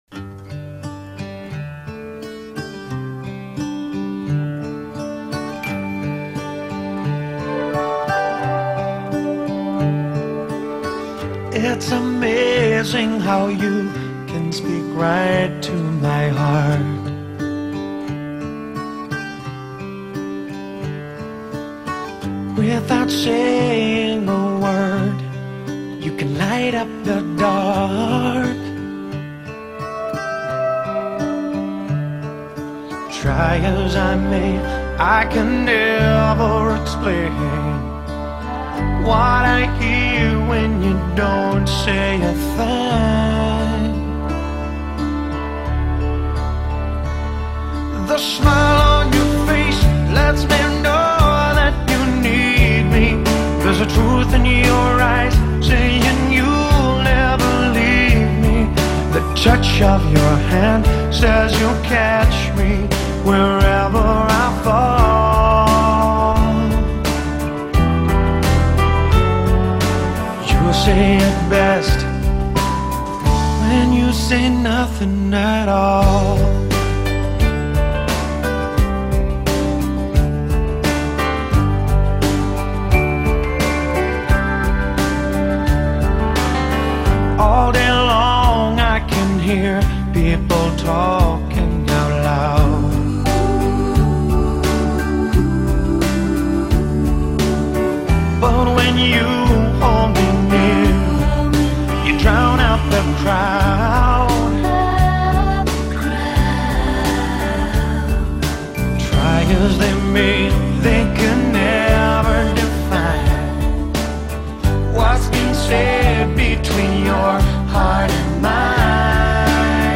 Wedding Band Music
First Dance: